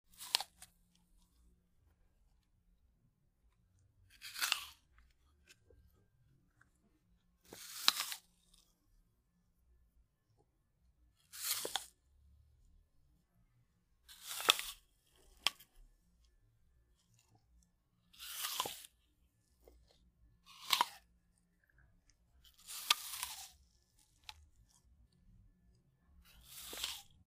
Звуки яблока
Хруст откусывания яблока